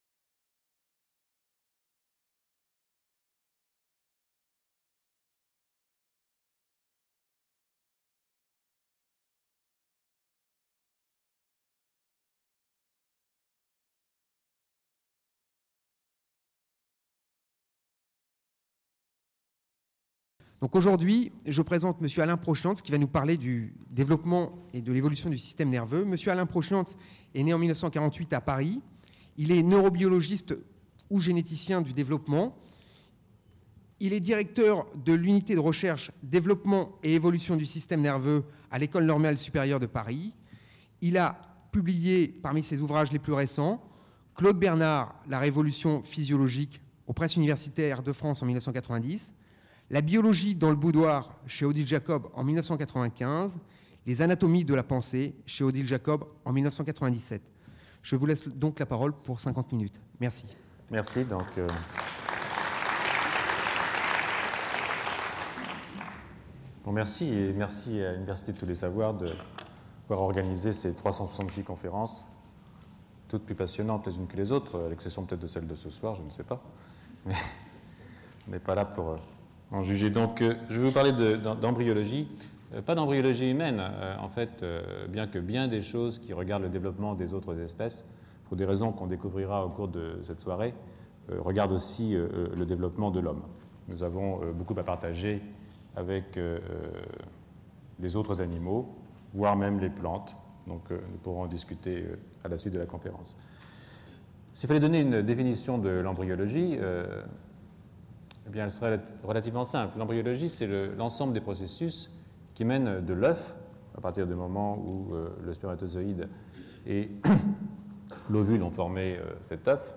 Conférence du 24 janvier 2000 par Alain Prochiantz.